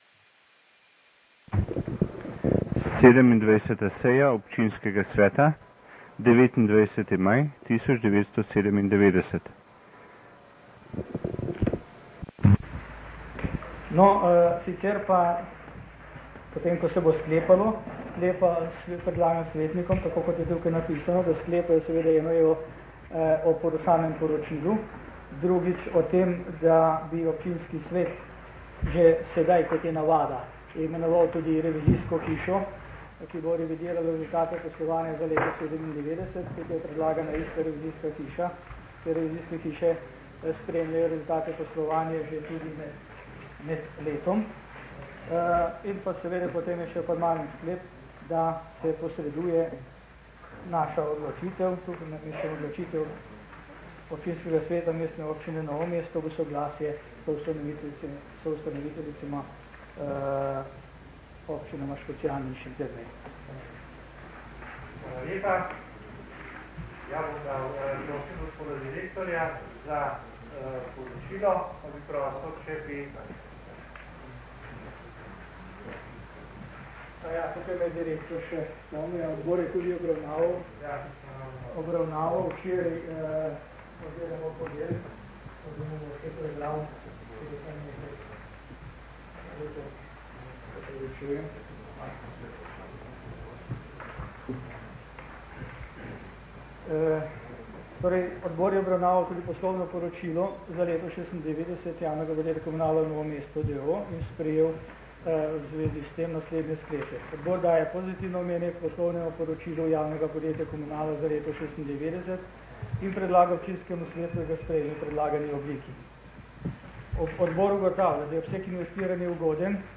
27. seja seja Občinskega sveta Mestne občine Novo mesto - Seje - Občinski svet - Mestna občina